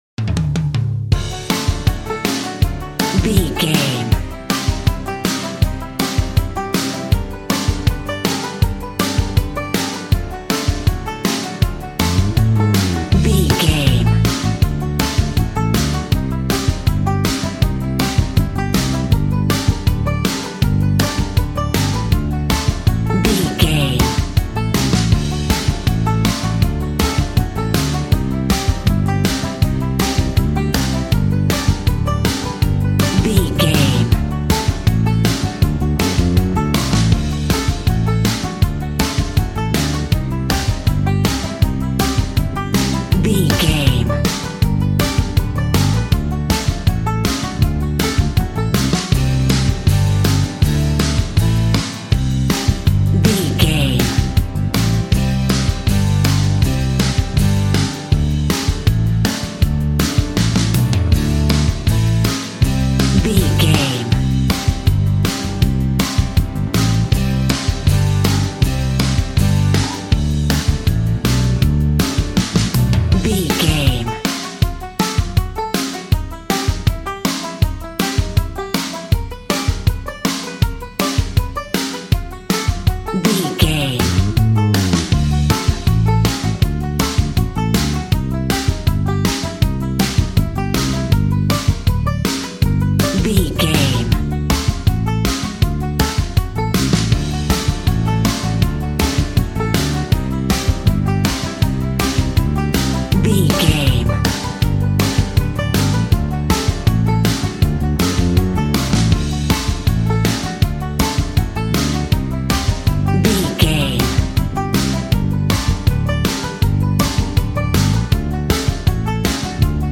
Ionian/Major
D
cool
uplifting
bass guitar
electric guitar
drums
cheerful/happy